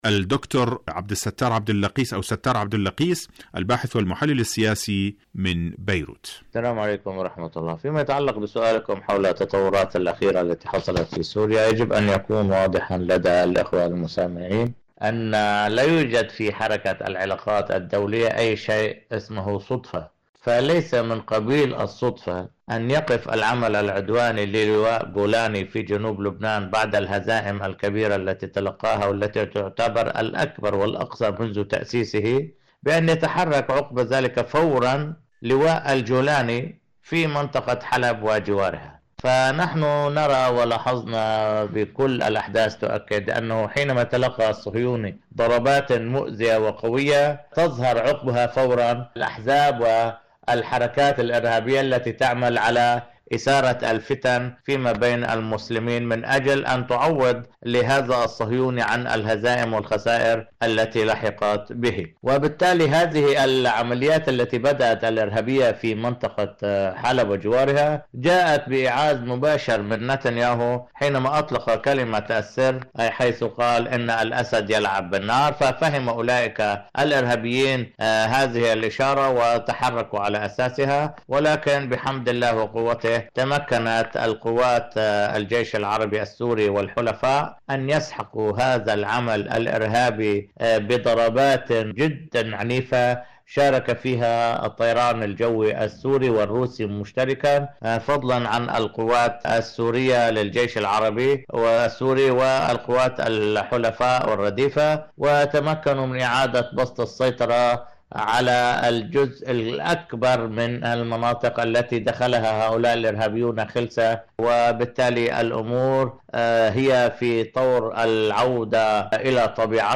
إذاعة طهران- حدث وحوار: مقابلة إذاعية